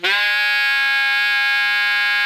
Index of /90_sSampleCDs/Giga Samples Collection/Sax/ALTO 3-WAY
ALTO GR G#4.wav